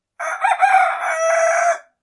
Rooster Sounds » Rooster Crow 2
描述：A rooster crowing.
标签： rooster animal crowing chicken farm
声道立体声